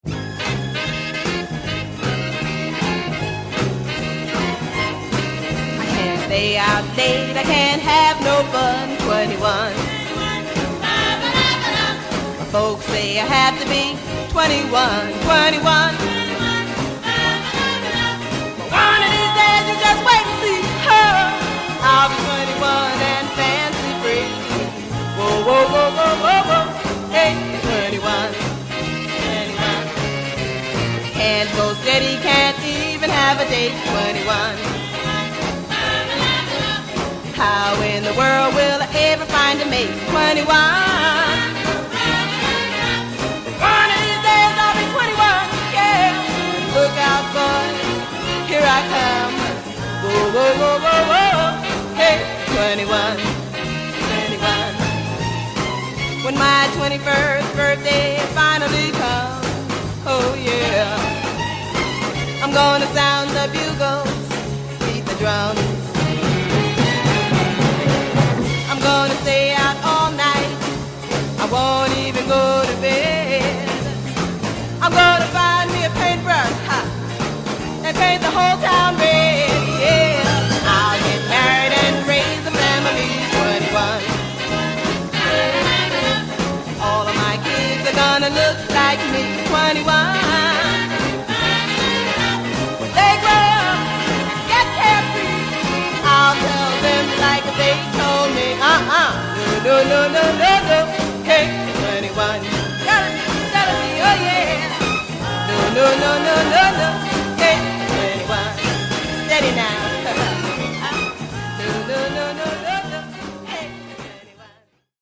(stereo version)